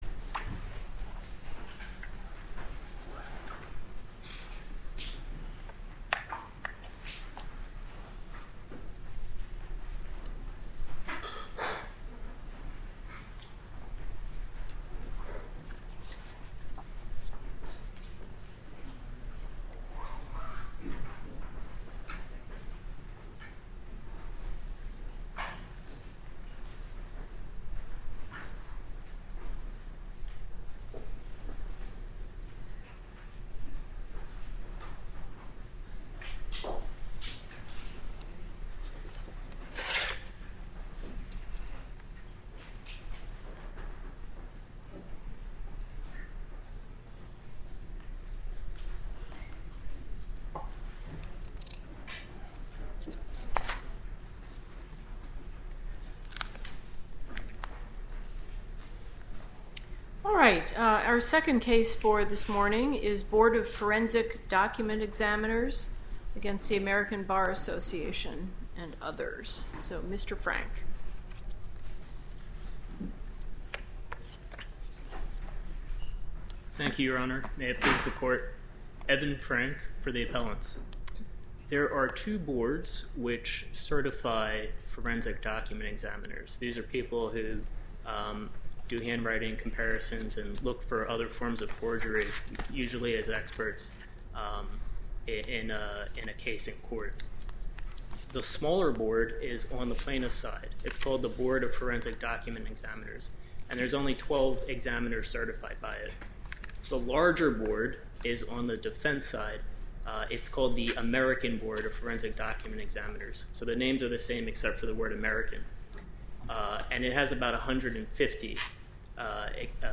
Oral Argument in 7th Circuit Case Finding Alleged Libel Against American Bar Association Was Non-Actionable Opinion